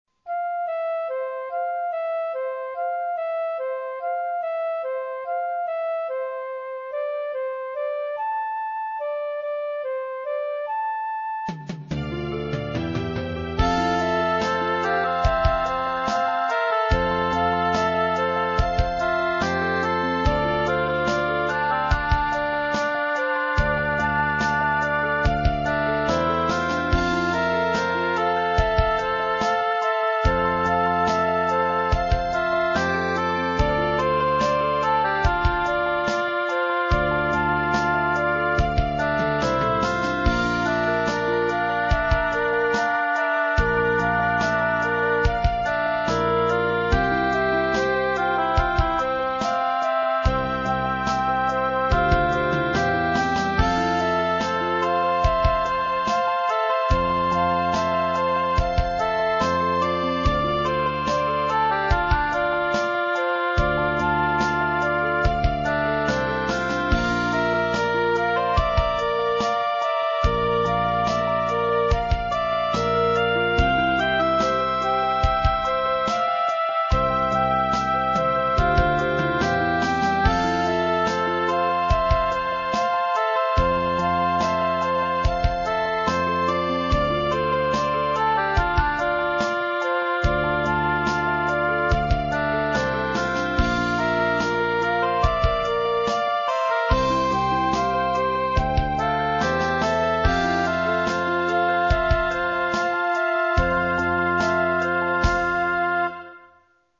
Roland D-70, AKAI SG-01k, Cakewalk Express